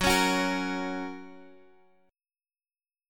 F# chord